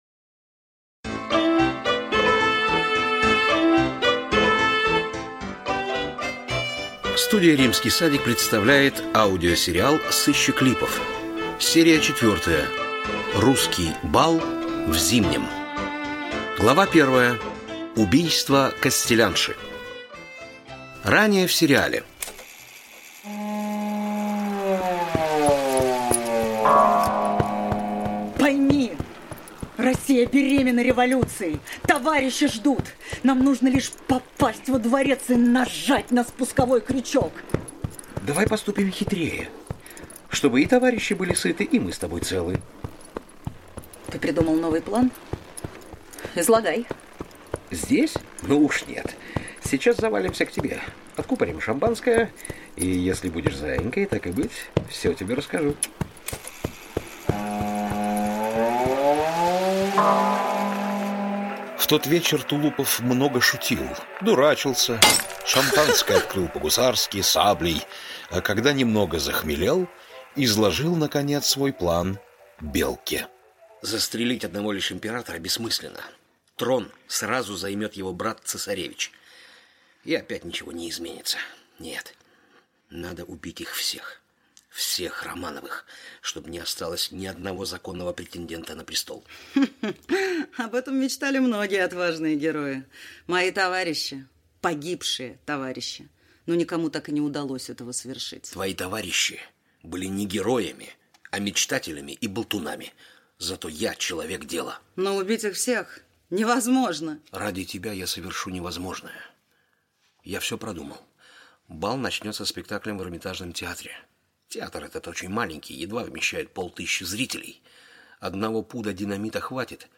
Аудиокнига Сыщик Липов.